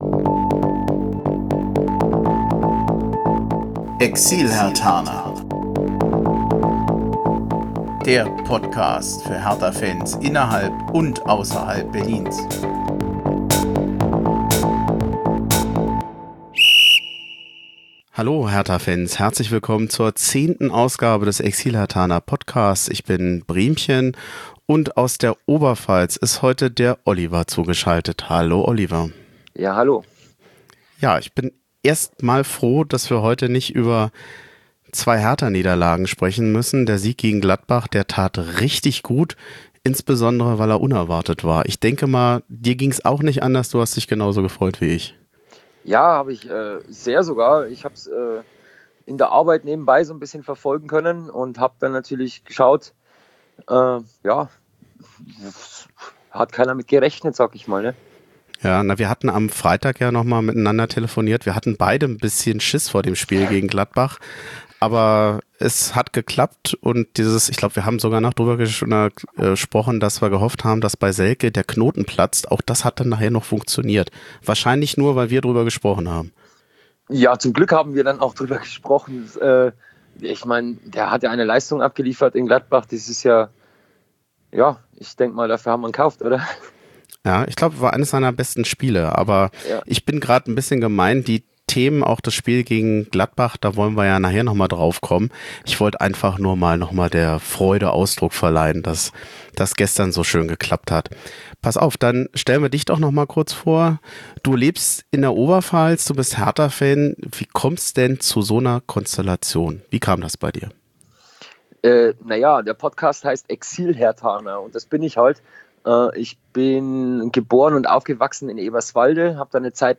Ein 3-Minuten-Beitrag zu den Reisekosten ist auch enthalten.
Quelle Pfiff hier .
Quelle Bahnhofsgeräusche hier . Quelle fahrender Zug hier .